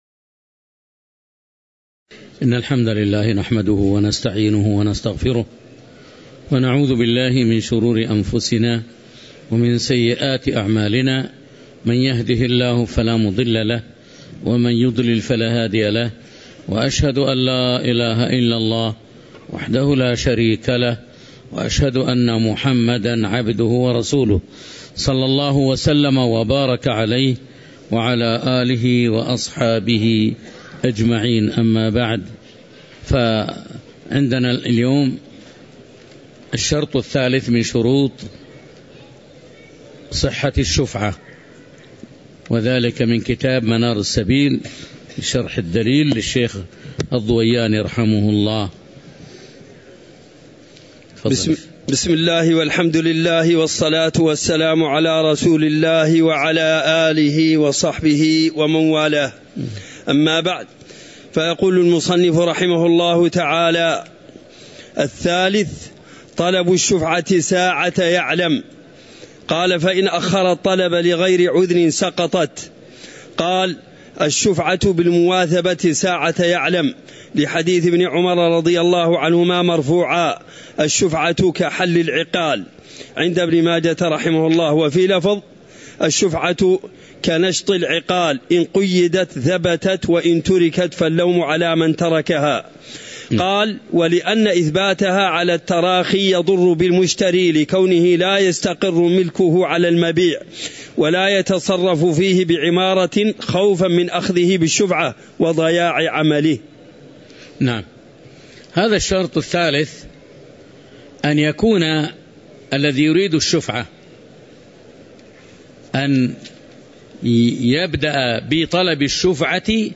تاريخ النشر ٢٣ شوال ١٤٤٣ هـ المكان: المسجد النبوي الشيخ